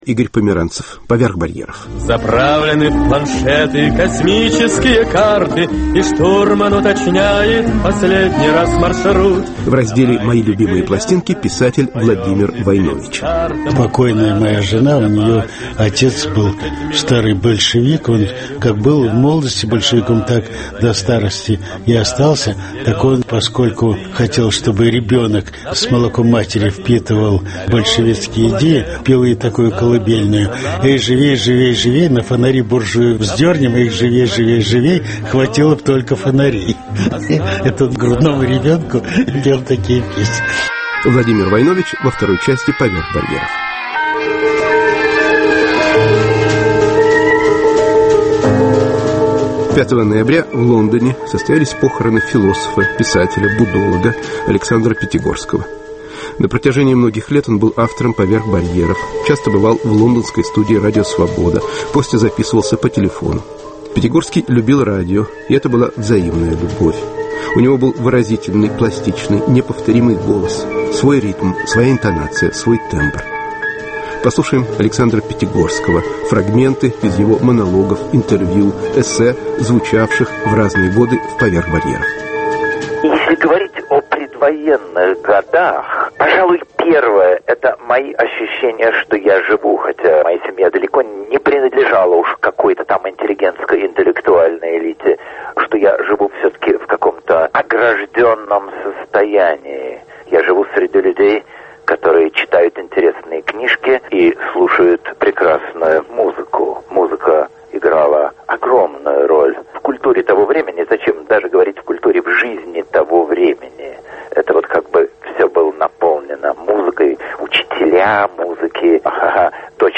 Памяти Александра Пятигорского: выступления философа в "Поверх барьеров": записи разных лет.